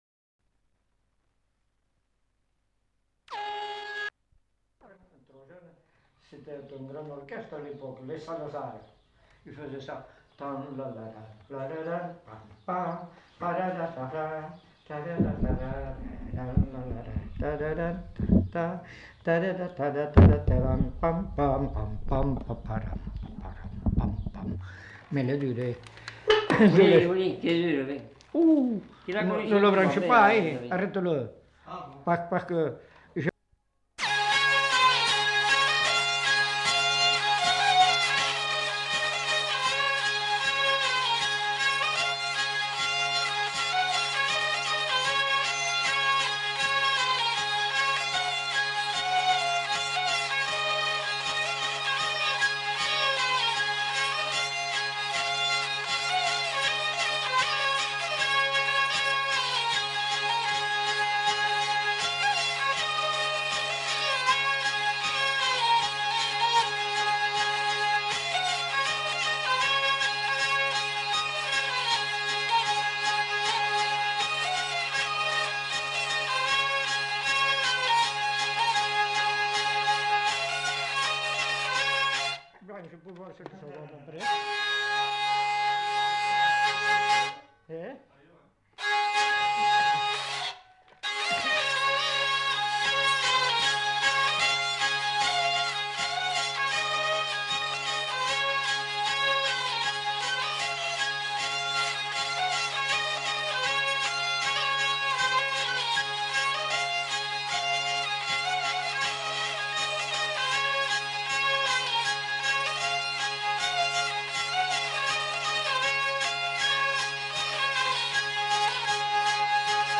Lieu : Vielle-Soubiran
Genre : morceau instrumental
Instrument de musique : vielle à roue
Danse : scottish